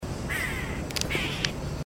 Boyero Negro (Cacicus solitarius)
Nombre en inglés: Solitary Cacique
Localidad o área protegida: Reserva Ecológica Costanera Sur (RECS)
Condición: Silvestre
Certeza: Vocalización Grabada